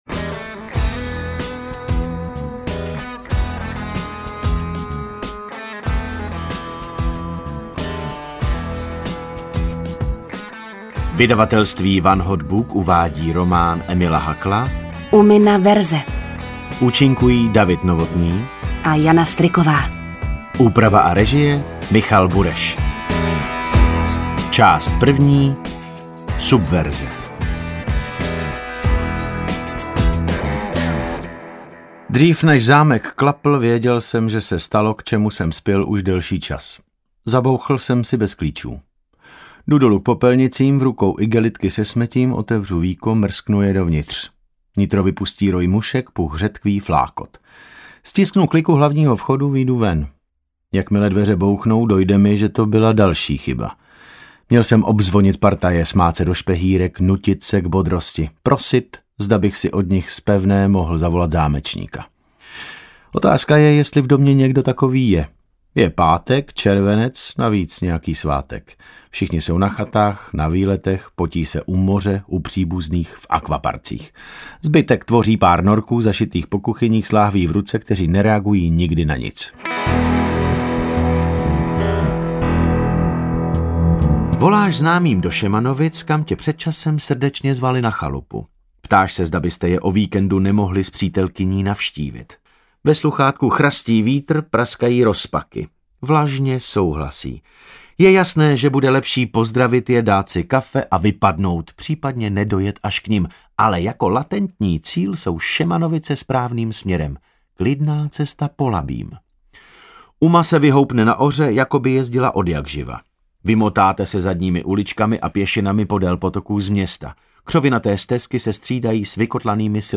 Umina verze audiokniha
Ukázka z knihy
• InterpretDavid Novotný, Jana Stryková